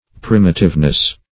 primitiveness - definition of primitiveness - synonyms, pronunciation, spelling from Free Dictionary
Primitiveness \Prim"i*tive*ness\, n.